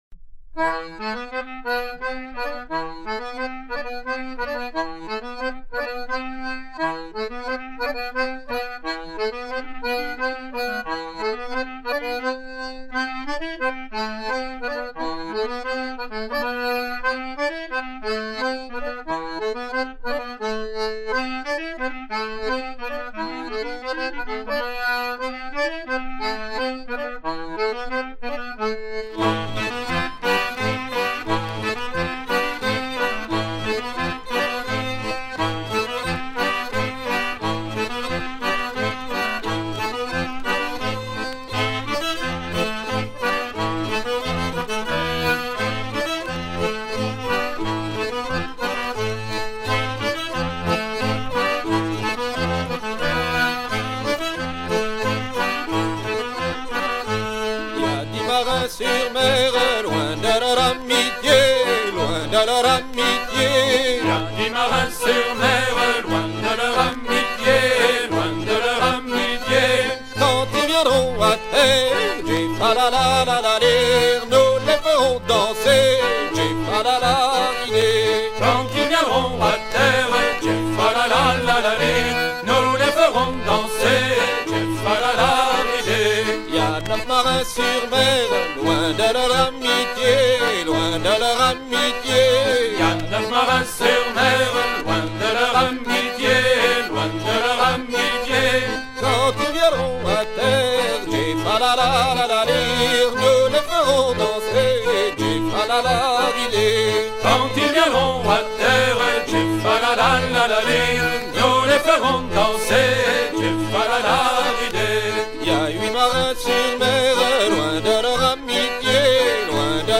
hanter-dro
Pièce musicale éditée